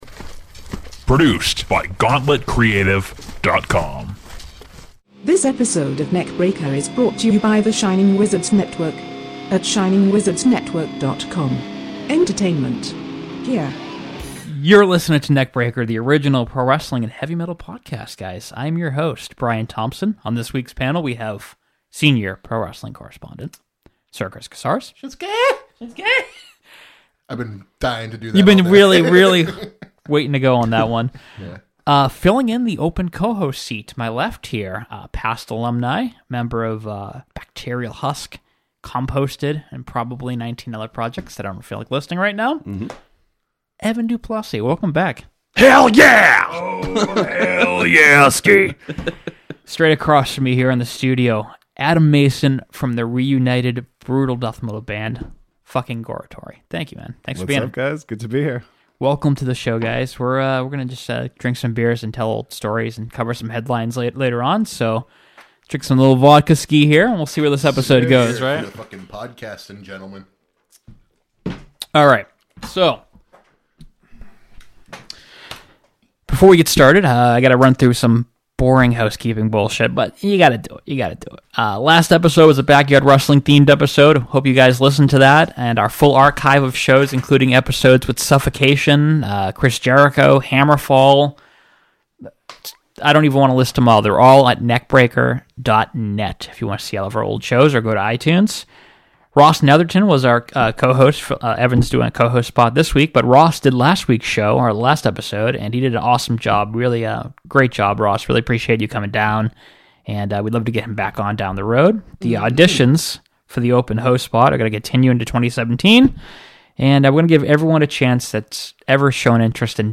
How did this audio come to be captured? visit the studio for a ridiculous conversation! They discuss Goratory’s upcoming appearance at Maryland Deathfest, share international tour stories, drink beer, and cover the latest metal and wrestling headlines.